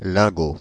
ÄäntäminenParis:
• IPA: [lɛ̃.go]